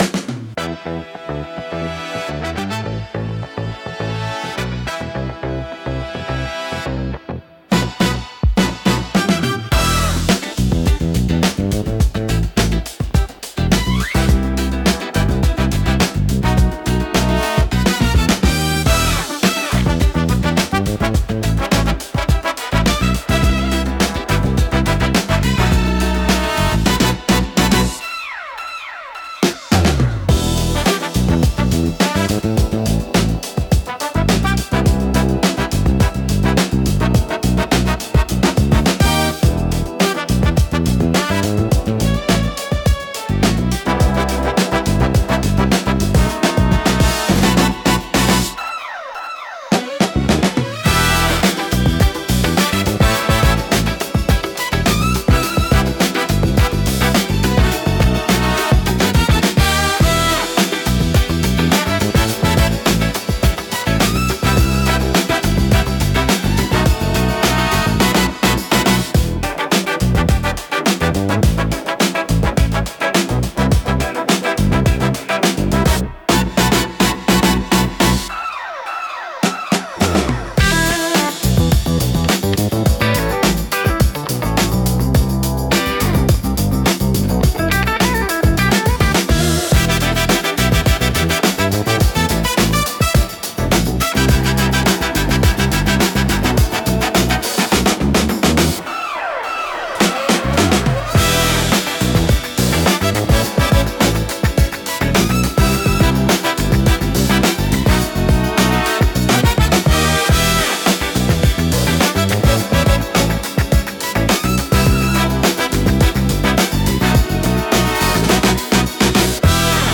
躍動感と活気に満ちたジャンルです。